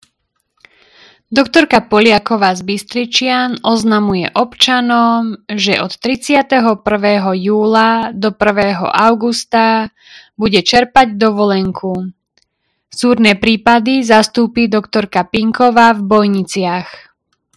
Hlásenie obecného rozhlasu